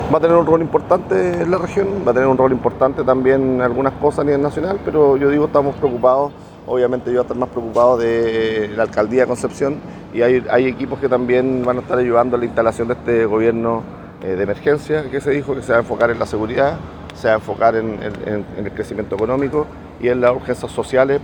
El alcalde de Concepción y vicepresidente del Partido Social Cristiano, Héctor Muñoz, aseguró que tiene confianza con el presidente electo y que muchas veces tuvieron reuniones incluso en su casa.